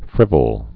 (frĭvəl)